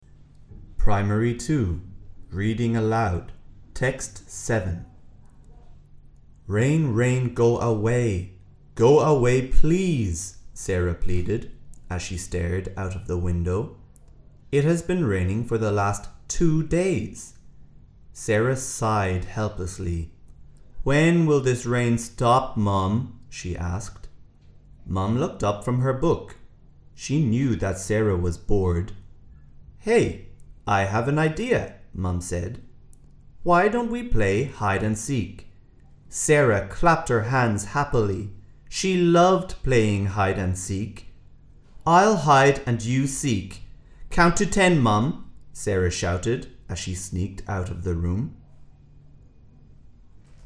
Reading Aloud
แบบฝึกการอ่าน และการออกเสียงภาษาอังกฤษ ปีการศึกษา 2568